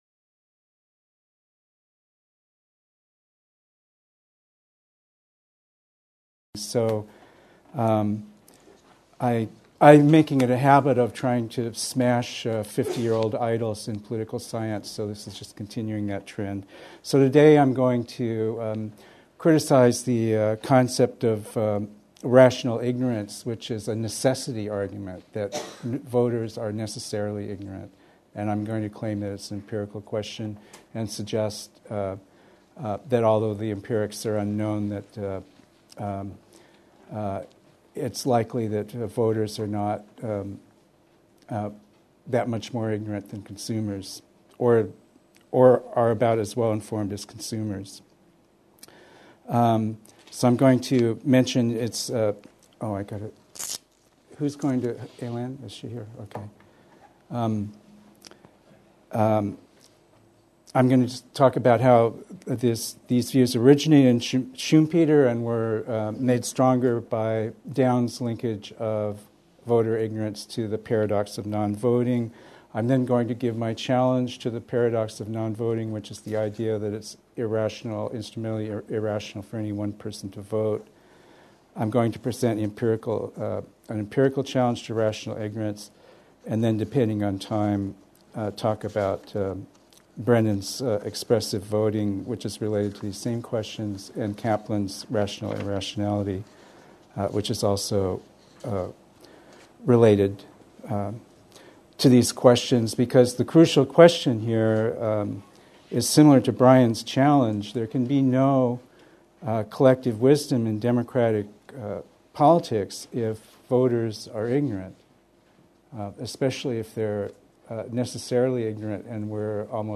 La sagesse collective : principes et mécanismes Colloque des 22-23 mai 2008, organisé par l'Institut du Monde Contemporain du Collège de France, sous la direction du Professeur Jon Elster.